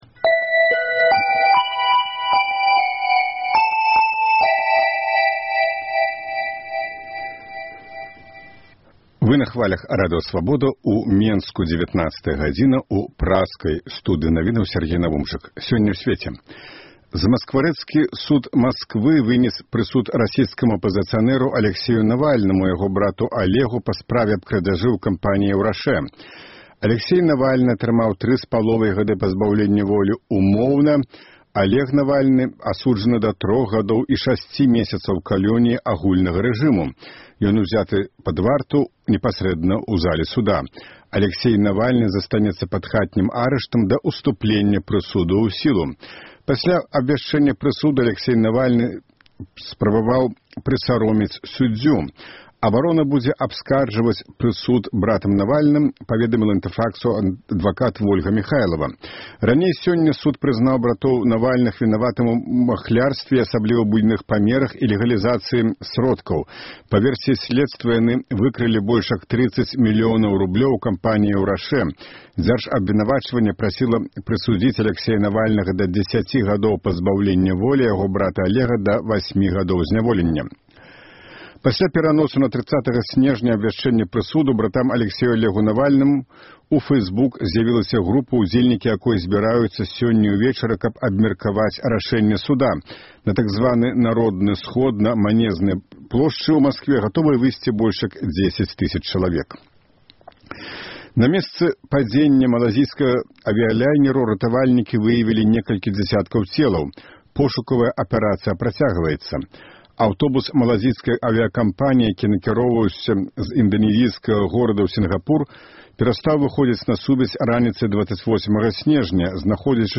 Навіны